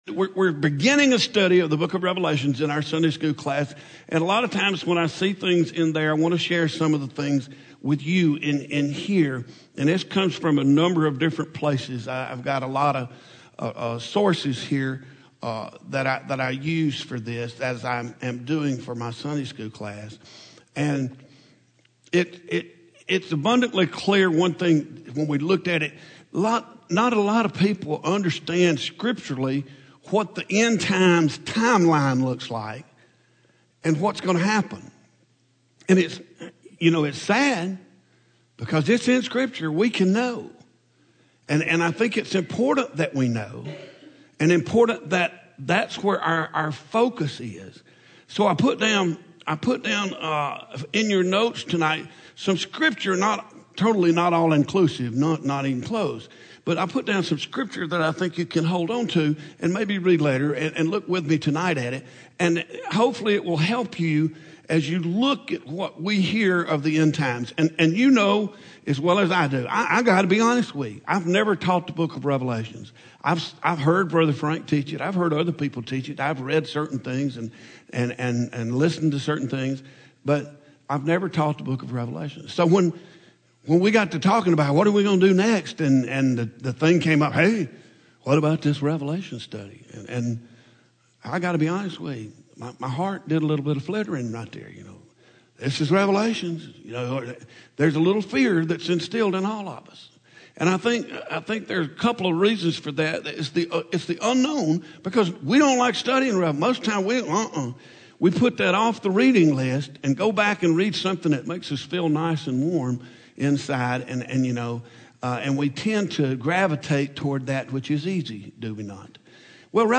The second coming of Jesus. Audio Sermon